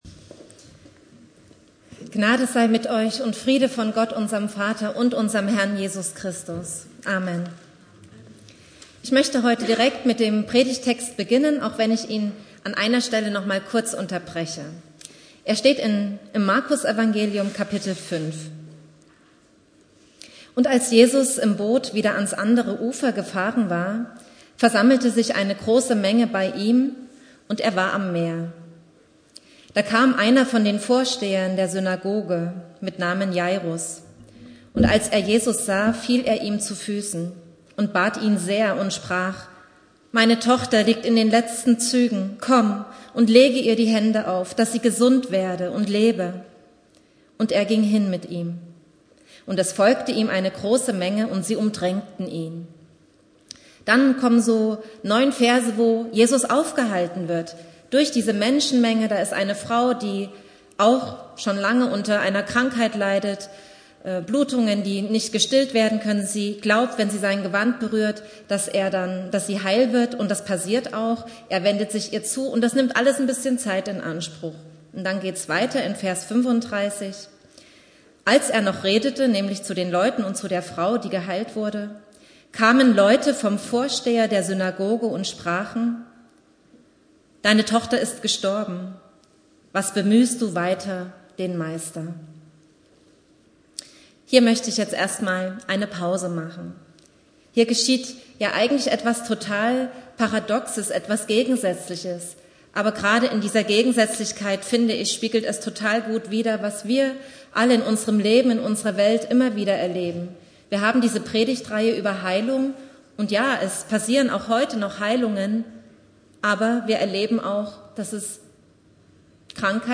Bibeltext: Markus 5,21-43 Predigtreihe: Begegnung und Heilung Dauer: 19:56 Abspielen: Ihr Browser unterstützt das Audio-Element nicht.